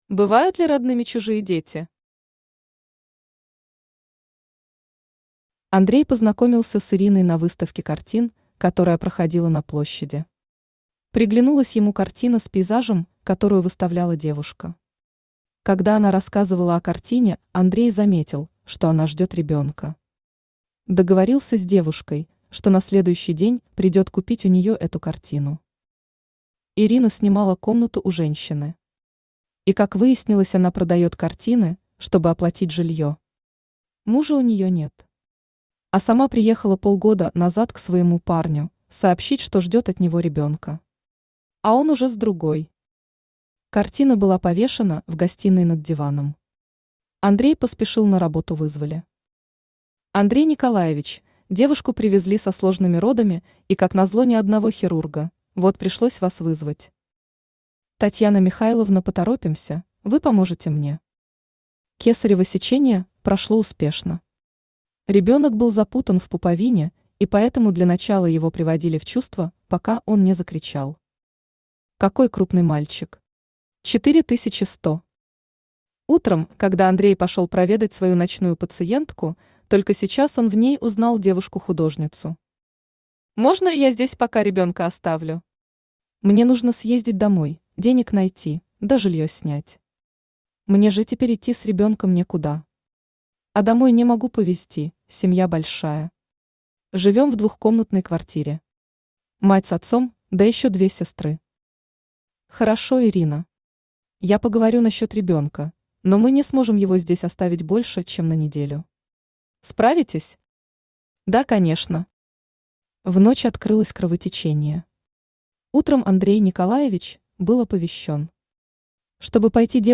Аудиокнига Реальные истории реальных людей 2 | Библиотека аудиокниг
Aудиокнига Реальные истории реальных людей 2 Автор Алёна RMK Читает аудиокнигу Авточтец ЛитРес.